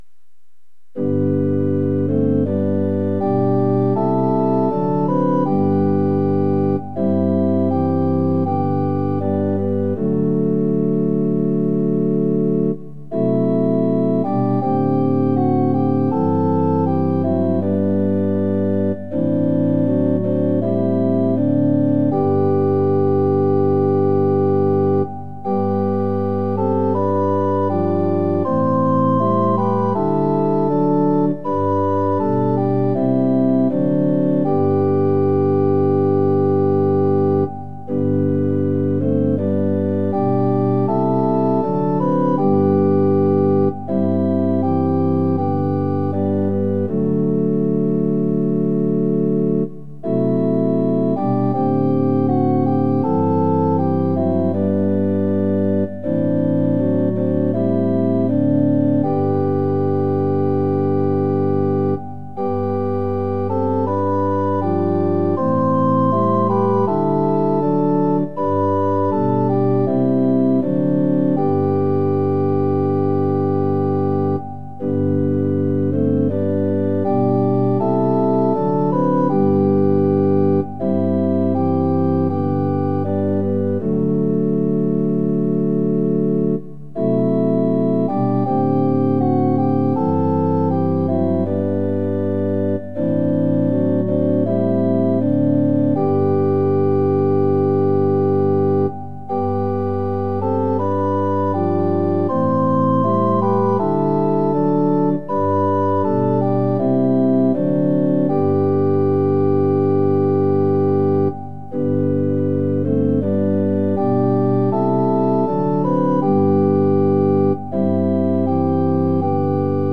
◆　４分の４拍子：　１拍目から始まります。